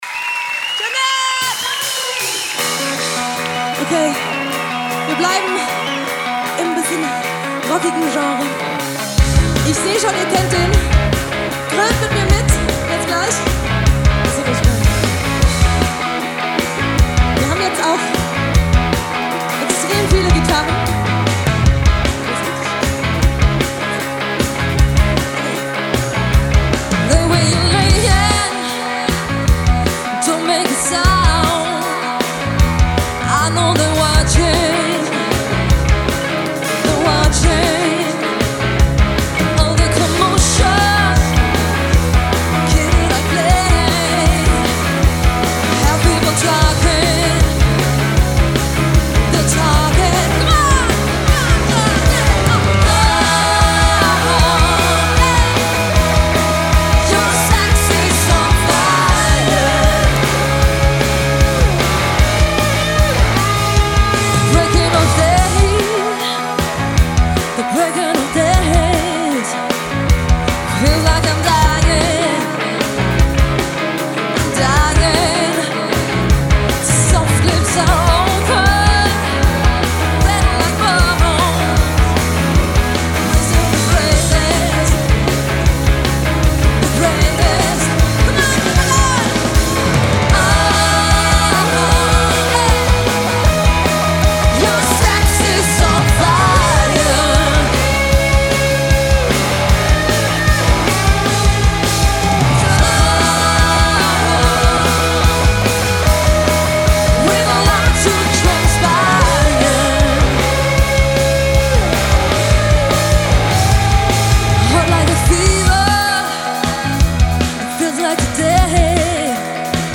premium live music entertainment